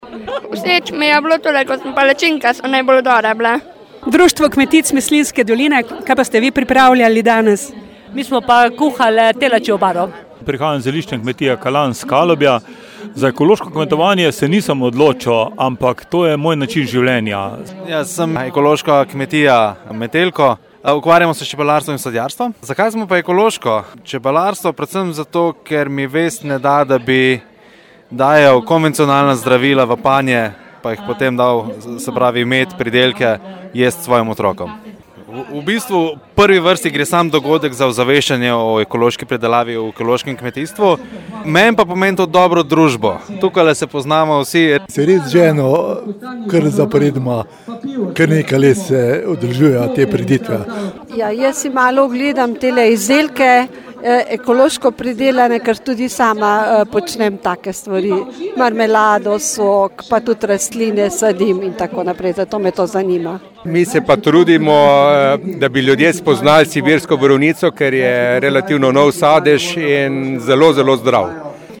Pomen praznika  ekoloških kmetij Slovenije je osveščanje. Ekofestival v Slovenj Gradcu, 12. festival ekoloških kmetij v organizaciji Združenja ekoloških pridelovalcev in predelovalcev Deteljica, je bil odmeven. Obiskovalci:
IZJAVE LJUDJE .MP3